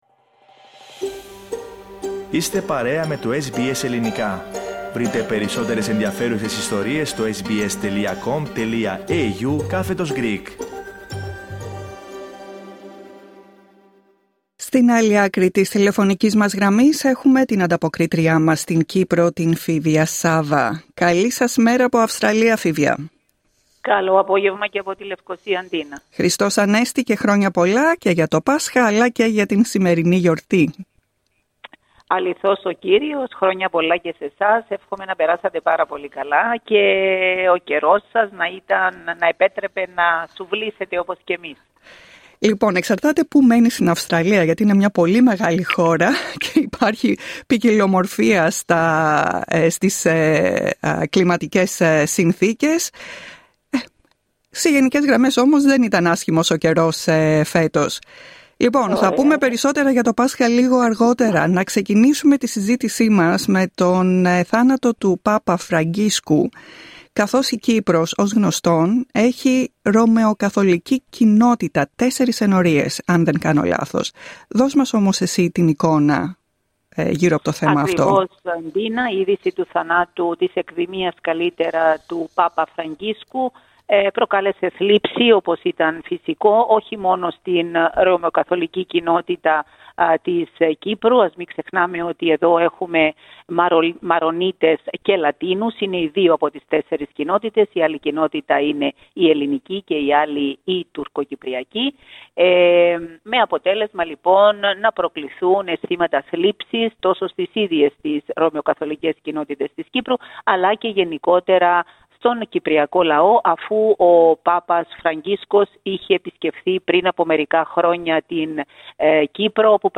Ακούστε τα υπόλοιπα θέματα της ανταπόκρισης από την Κύπρο, πατώντας PLAY δίπλα από την κεντρική φωτογραφία.